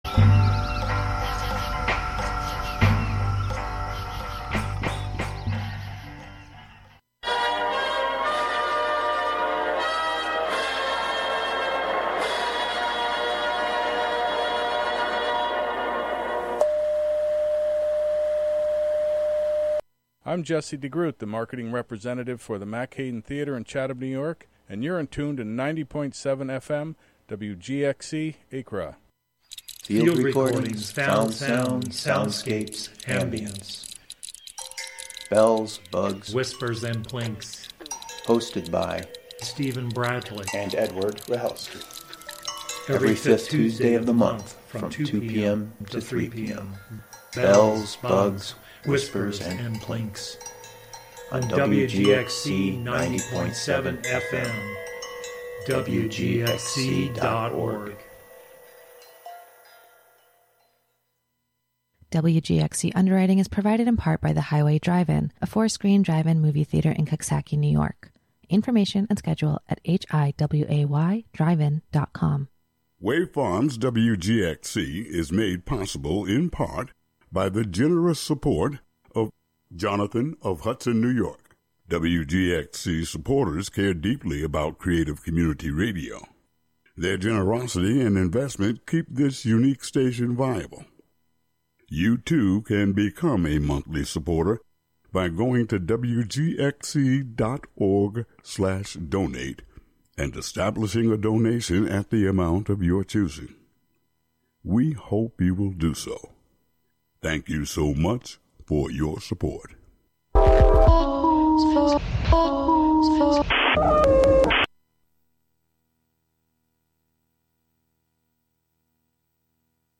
Audio Buffet: mourning/morning/songs: a conversation & reading w